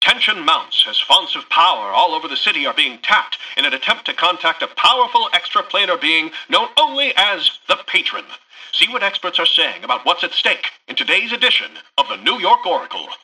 Newscaster_headline_25.mp3